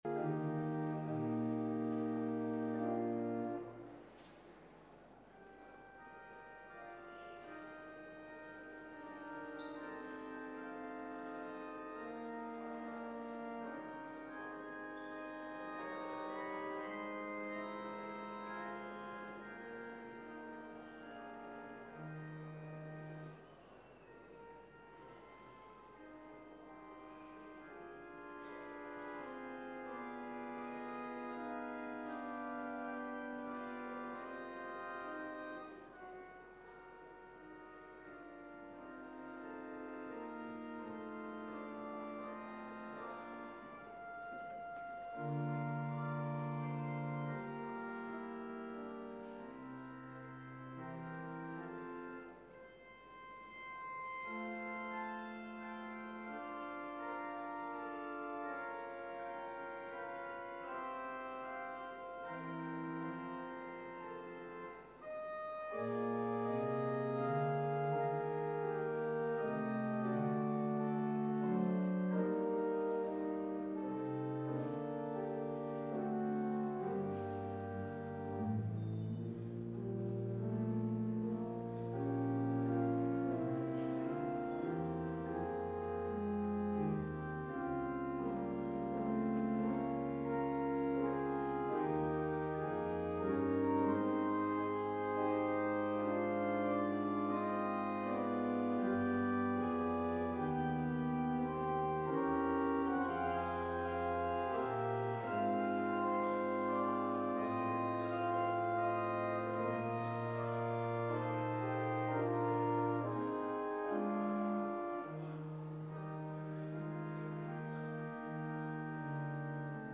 Join us as we take a look at Ephesians 4:1-16 in a sermon titled “No Walls Will Divide”.
Full Service Audio